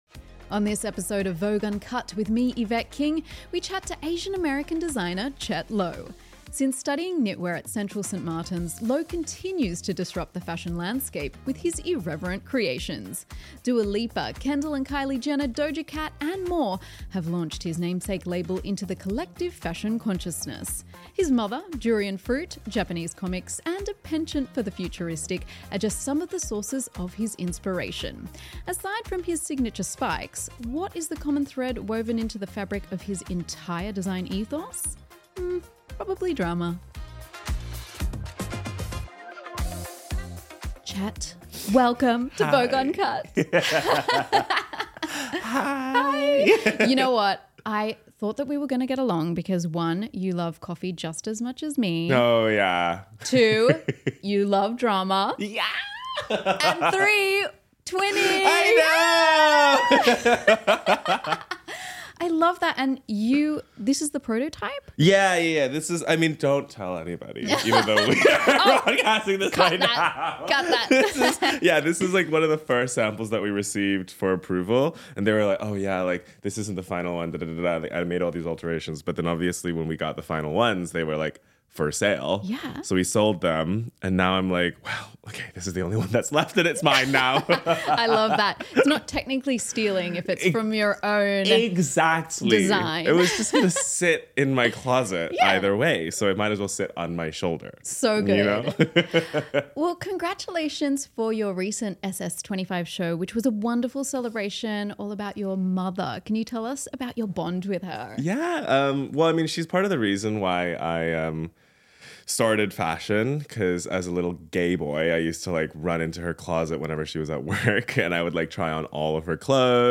Produced in collaboration with Poddster, the Next in Vogue edition of Vogue Uncut features candid conversations with global and local trailblazers shaping fashion, culture, innovation and technology.